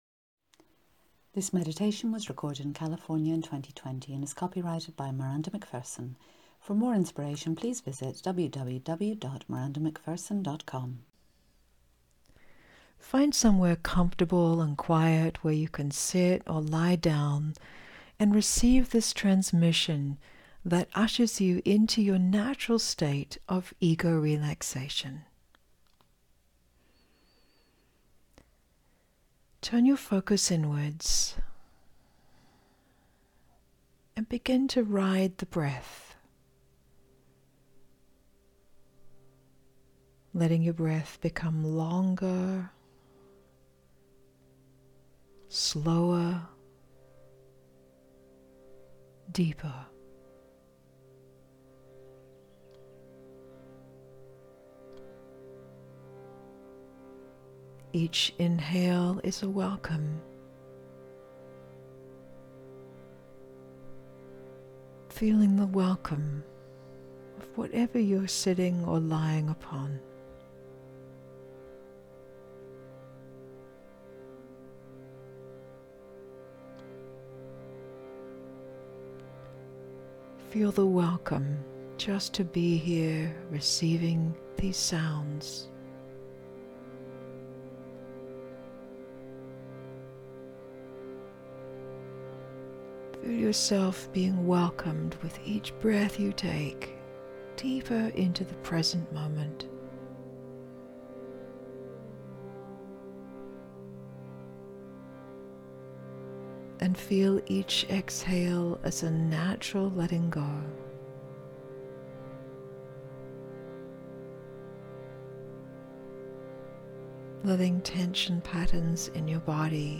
DOWNLOADABLE MEDITATION